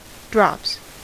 Ääntäminen
Ääntäminen US Tuntematon aksentti: IPA : /ˈdɹɑps/ IPA : /ˈdɹɒps/ Haettu sana löytyi näillä lähdekielillä: englanti Käännöksiä ei löytynyt valitulle kohdekielelle.